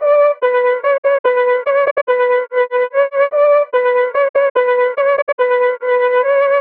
Each sample is meticulously crafted to help you achieve the same hard-hitting and soulful vibe that Divine is known for.
Gully-Loops-Drill-Melody-Loop-BPM-145-B-Min.wav